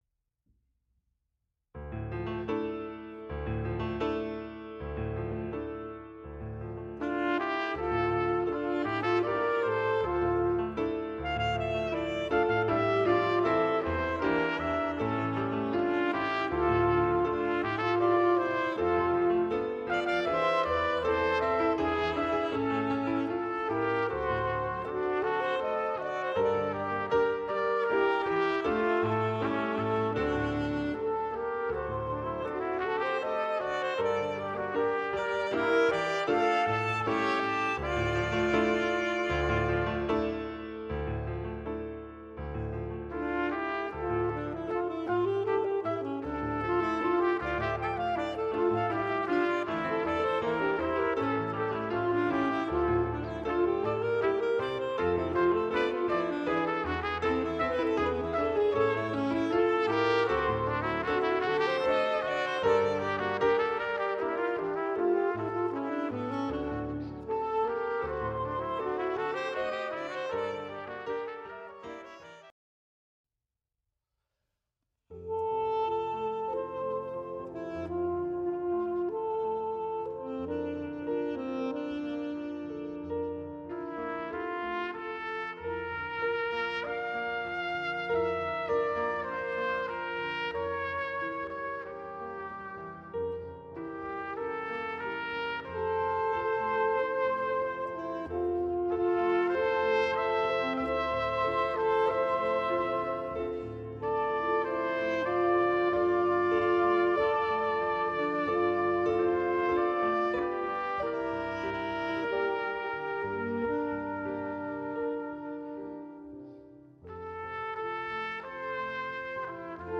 Voicing: Mixed Ensemble